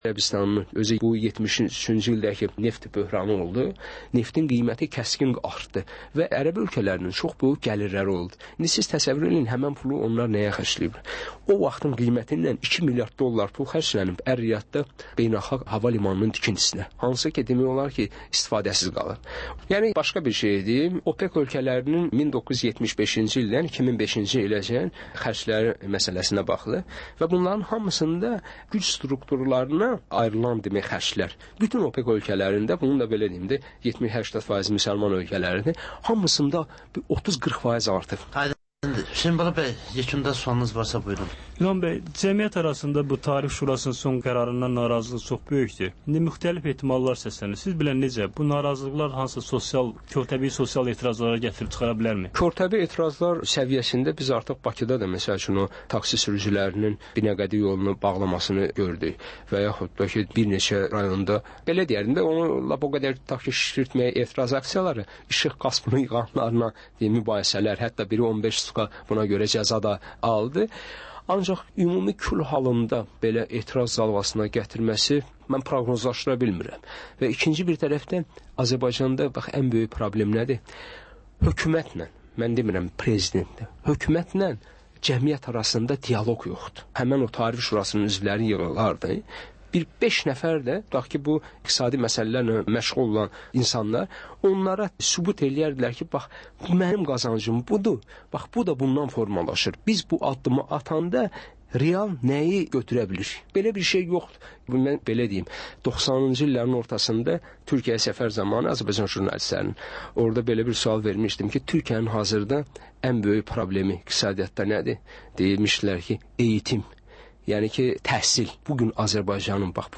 Jurnalistlər və həftənin xəbər adamıyla aktual mövzunun müzakirəsi